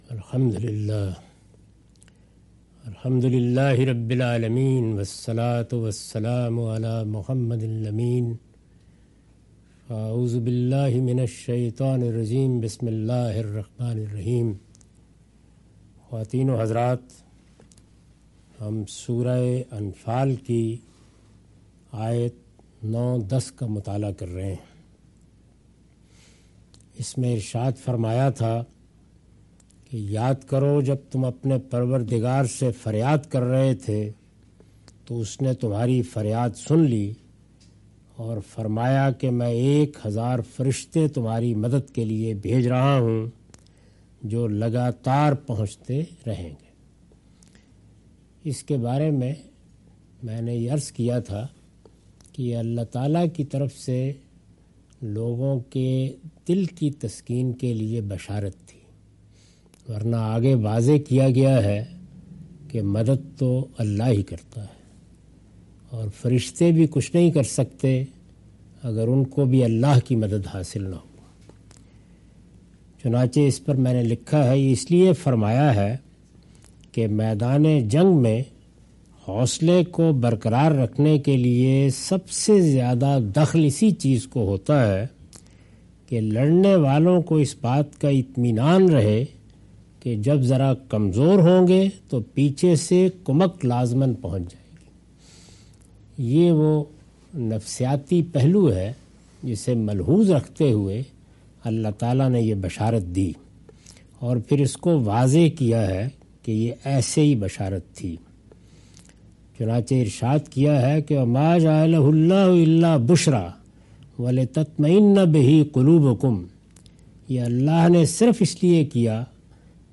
Surah Al-Anfal - A lecture of Tafseer-ul-Quran – Al-Bayan by Javed Ahmad Ghamidi. Commentary and explanation of verses 10-16.